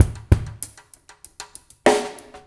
PRP DR2DRY-R.wav